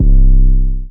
808 [ audio ].wav